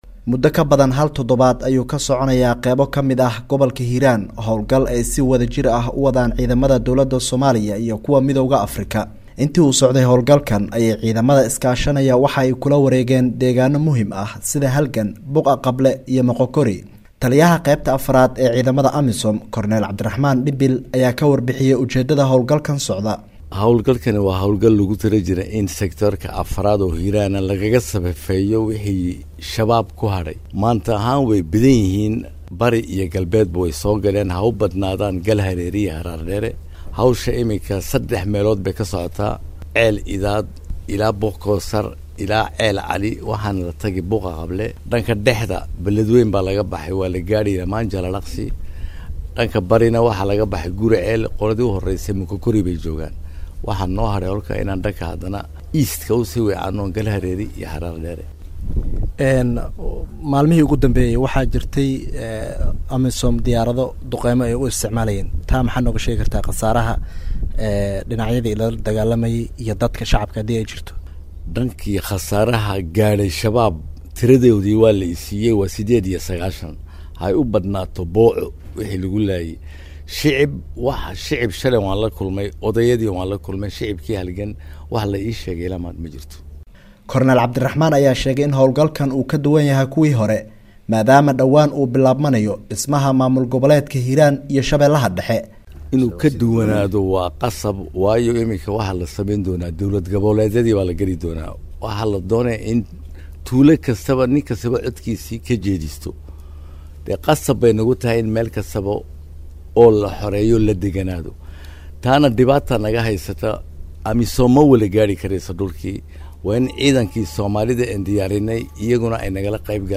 Wareysiga Amisom